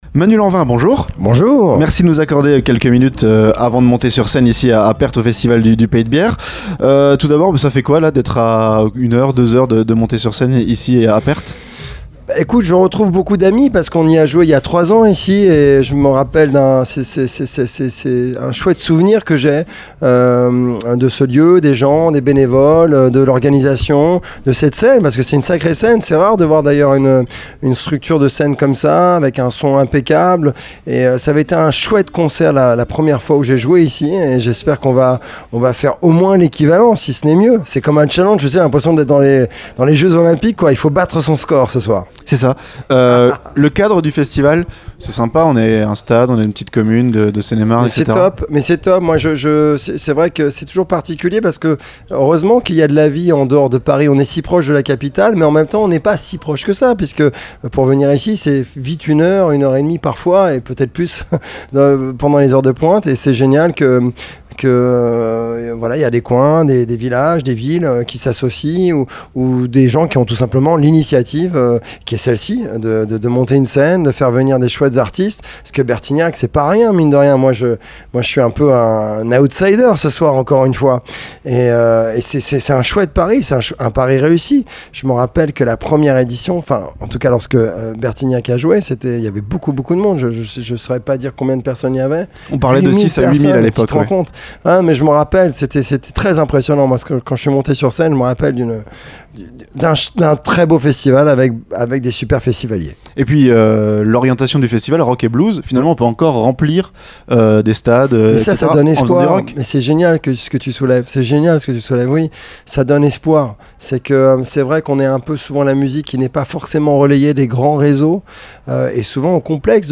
ENTRETIEN - Manu Lanvin, le blues, le rock et son père
Notre entretien exclusif avec Manu Lanvin, à l'occasion de son concert au festival du Pays de Bière à Perthes-en-Gâtinais vendredi 16 septembre, quelques minutes avant Louis Bertignac. Il évoque le festival, sa carrière, son père Gérard, avec qui il sort l'album Ici-bas, pour lequel il met en musique les textes de l'acteur.